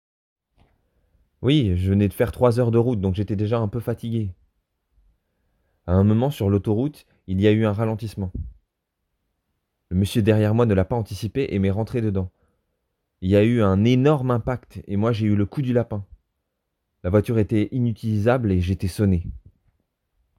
Essai Motion Voix off